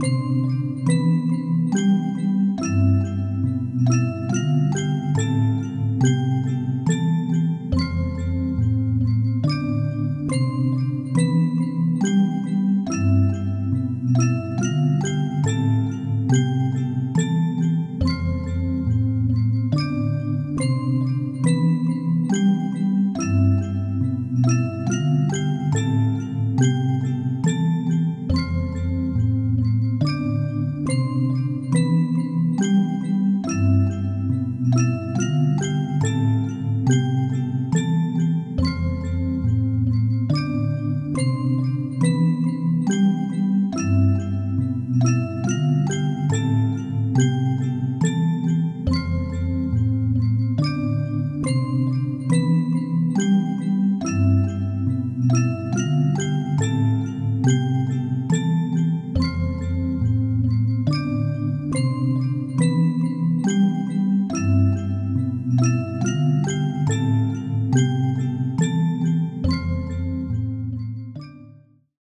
Type BGM
Speed 50%